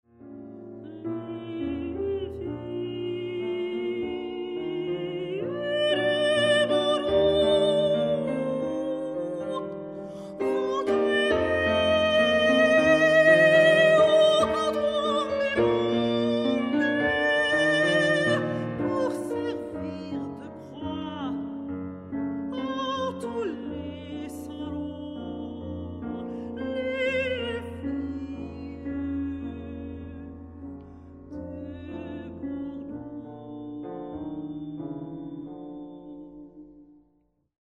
Klavier
Aufnahme: 1996, Bauer Tonstudios Ludwigsburg